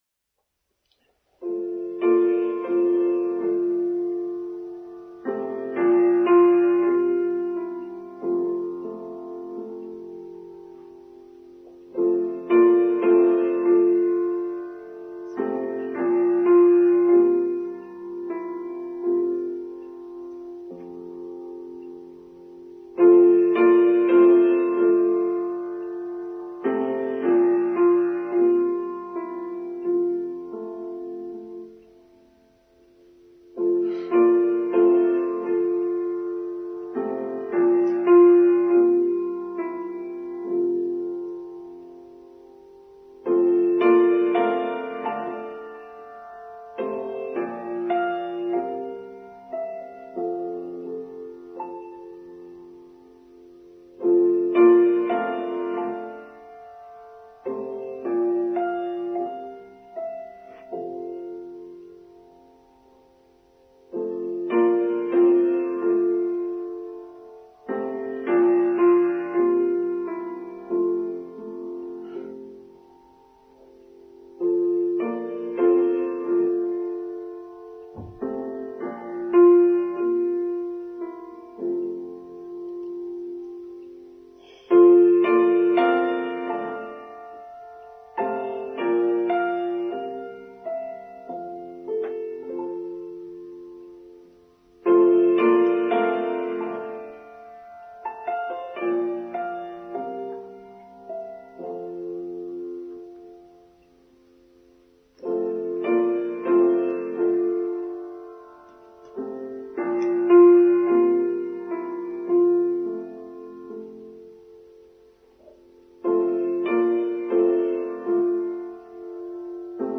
Process Is Important Too: Online Service for Sunday 18th September 2022